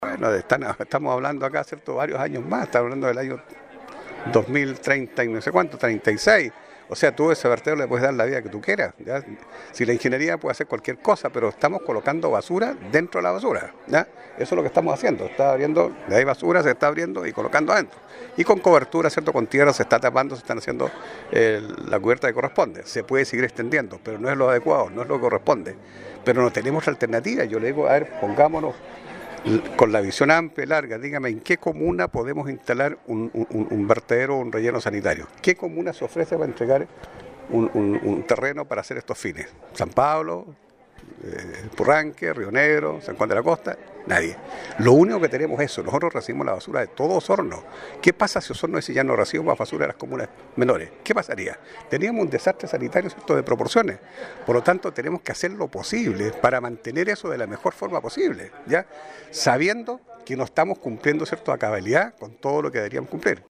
El alcalde de Osorno explicó que mientras no se avance en la construcción de un relleno sanitario moderno, se debe seguir prolongando la vida útil del actual relleno, situación condicionada además por ser el único en toda la provincia-